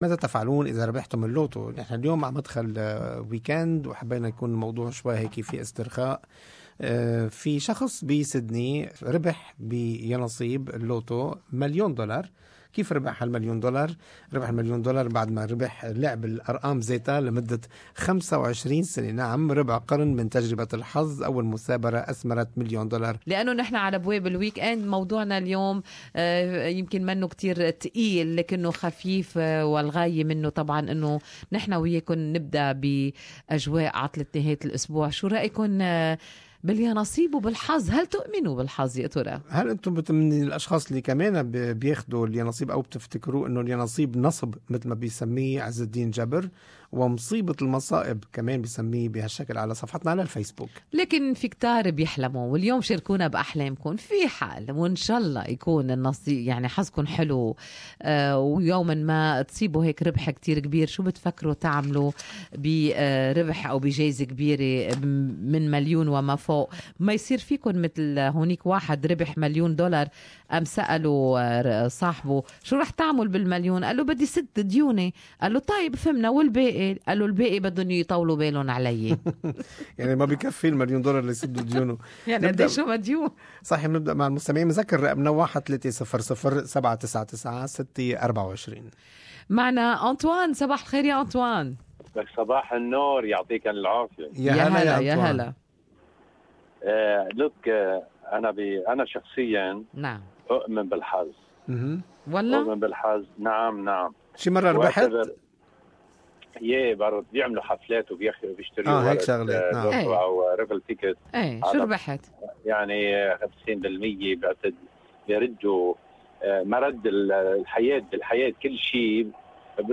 لنستمع إلى هذا الرابط الصوتي في حوار مباشر مع المستمعين.